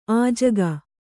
♪ ājaga